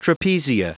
Prononciation du mot trapezia en anglais (fichier audio)
Prononciation du mot : trapezia